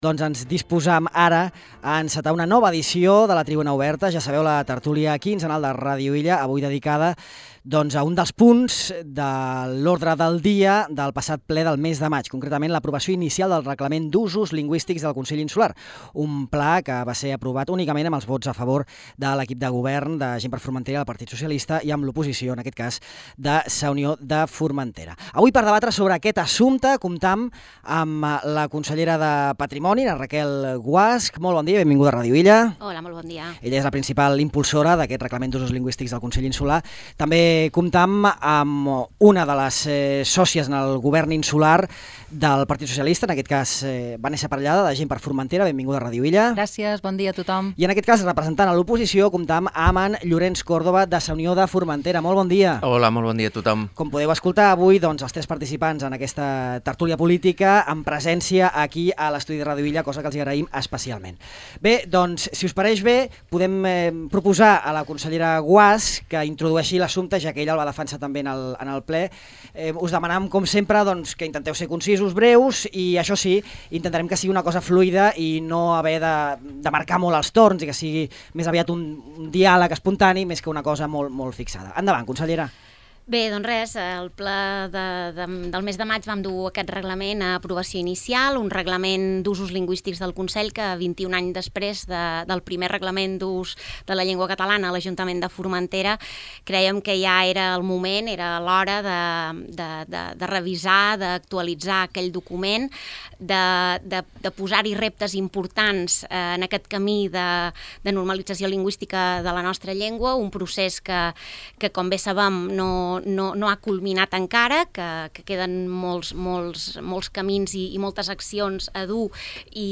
L’ús del català al Consell de Formentera ha centrat el debat d’aquesta nova edició de Tribuna Oberta, la tertúlia política quinzenal de Ràdio Illa.
Raquel Guasch, consellera de Patrimoni i representant del PSOE; Llorenç Córdoba, conseller de Sa Unió; i Vanessa Parellada, consellera de Joventut de GxF; opinen sobre la projecció del català i el recentment aprovat Reglament d’usos lingüístics del Consell, que comptà amb el vot favorable del Govern de coalició i el vot en contra de l’oposició.